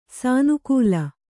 ♪ sānukūla